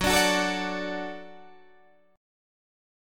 F#m#5 chord